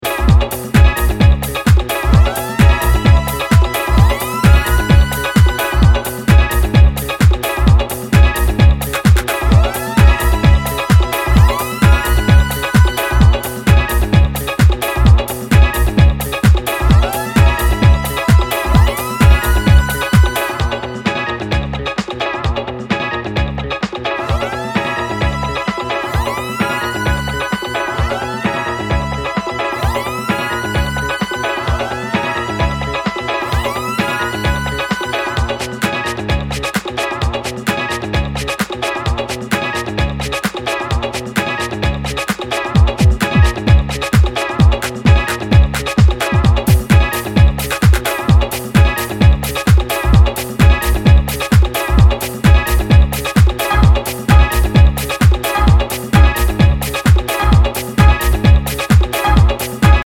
執拗なディスコサンプルの反復で畳み掛ける